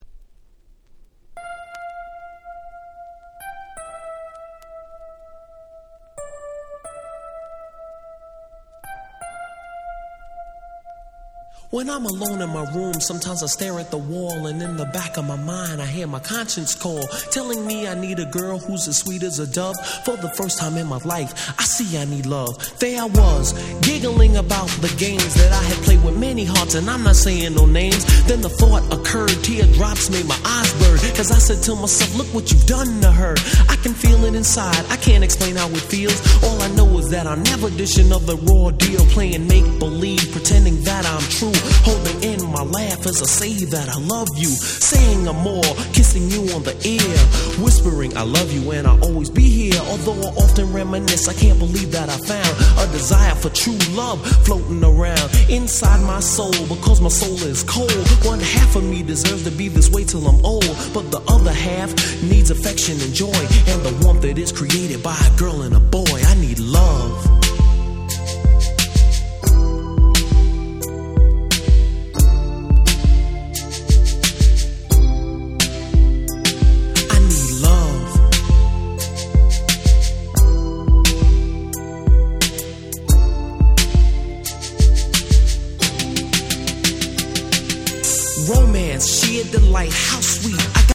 87' Hip Hop Super Classics !!
ミドル